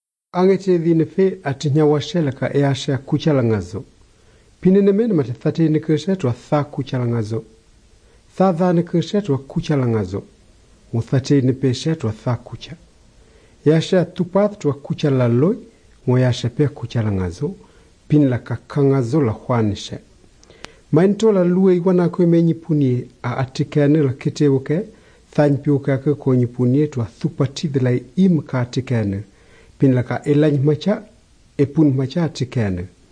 Those voiceless dental fricatives are pretty pronounced.